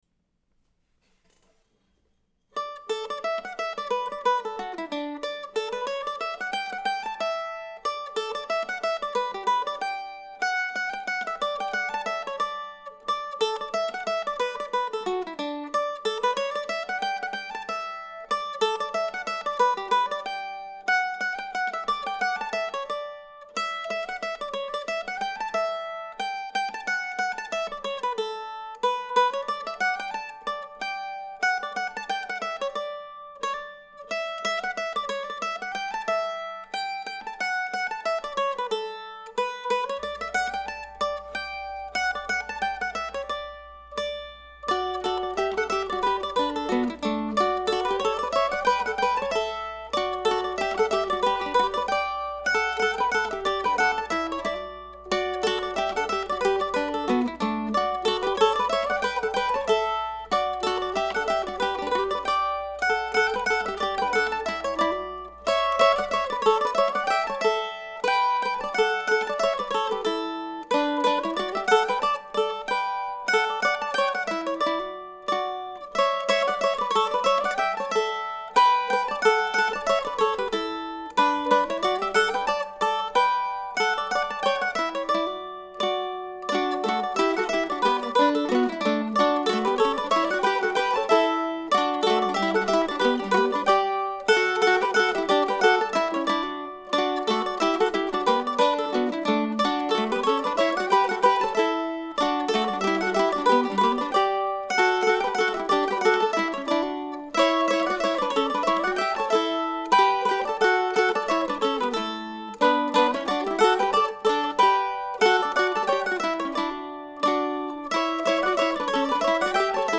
Merrills Mills ( mp3 ) ( pdf ) A jolly little tune titled after another obscure location in southern Piscataquis County, Maine.
(Note: In a highly unusual move I decided this morning [Monday, 9-25] that the second mandolin part was a little outside of my low standard of acceptability. So I have re-recorded that and re-mixed the tune.